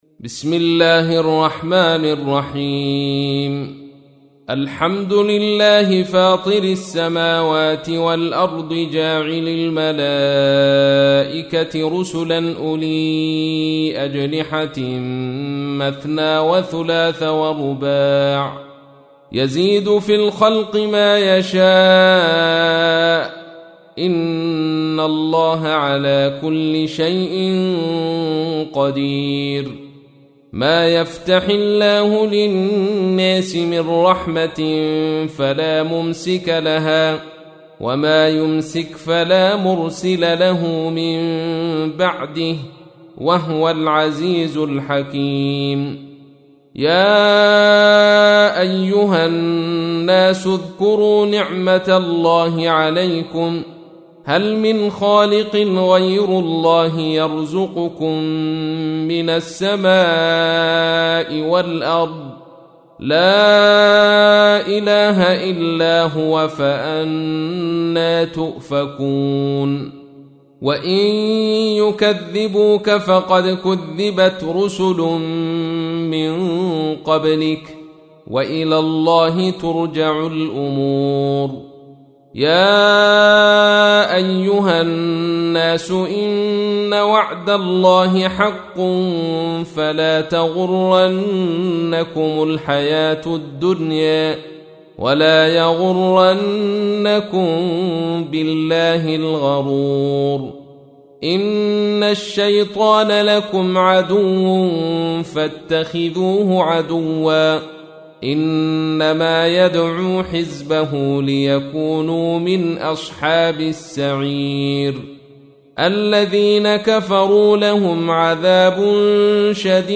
تحميل : 35. سورة فاطر / القارئ عبد الرشيد صوفي / القرآن الكريم / موقع يا حسين